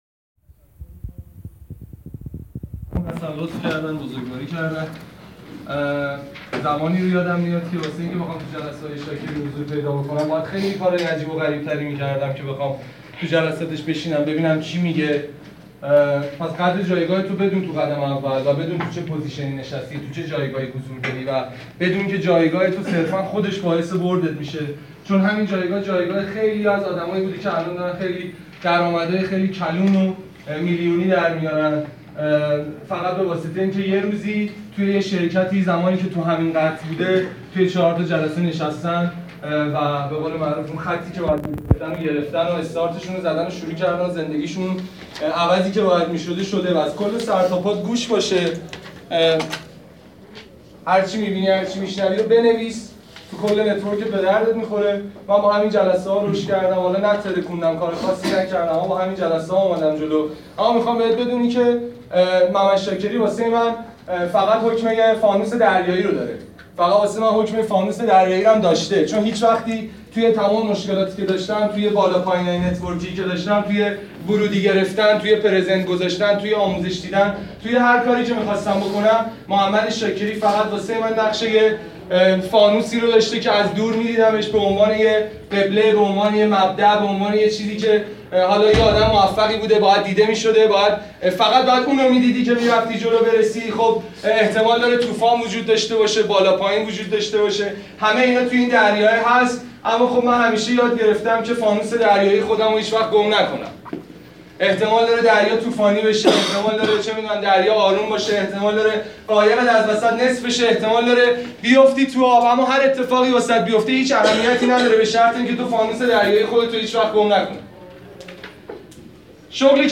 زیرا شما هر روز به اطلاعات جدید نیاز دارید، هر کلمه ای که یاد بگیرید میتونه روند نتورکتون رو تغییر بده، پس با گوش دادن به این فایل صوتی بسیار فالویی، که در جلسه ای حرفه ای ضبط شده اطلاعات خود را افزایش دهید و در امر فالو حرفه ای تر شوید.